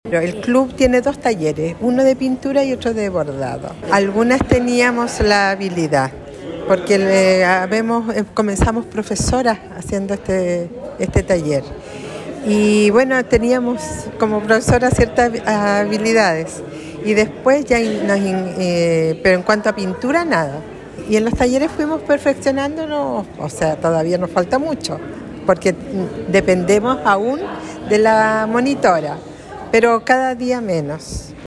actualidad Entrevista Local